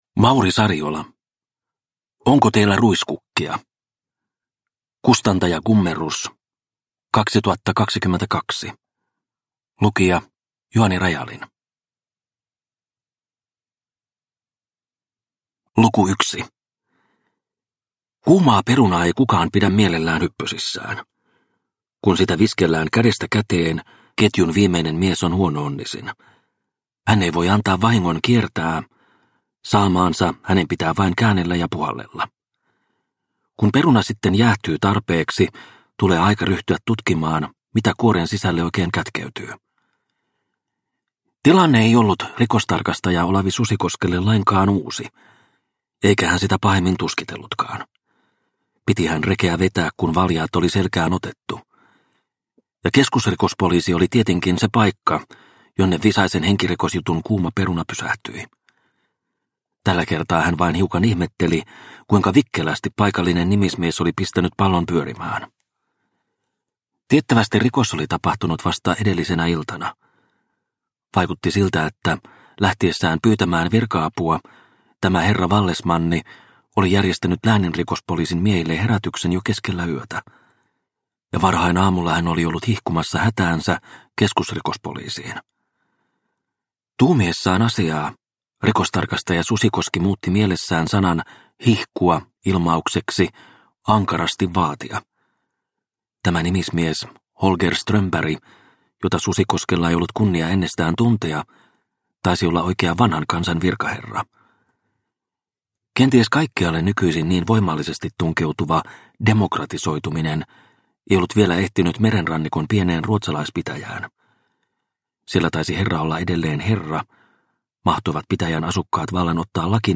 Onko teillä ruiskukkia? – Ljudbok – Laddas ner